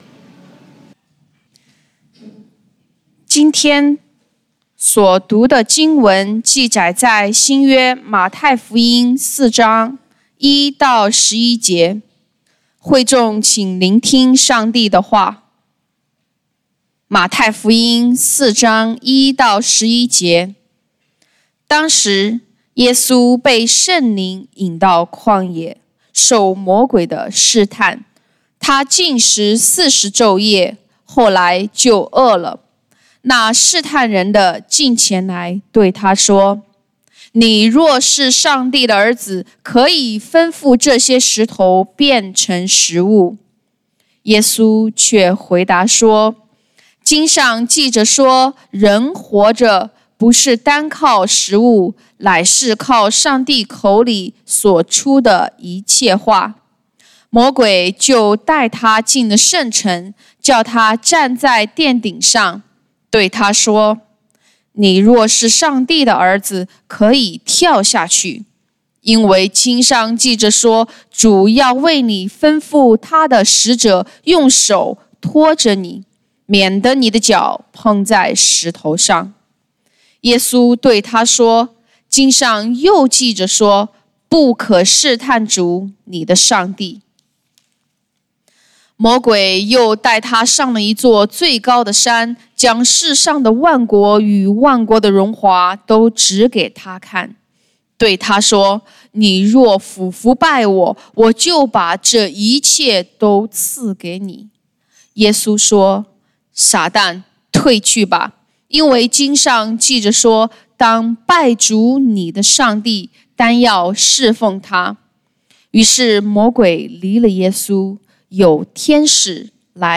講道經文：《馬太福音》Matthew 4:1-11 本週箴言：《雅各書》James 4:7-8 「你們要順服上帝。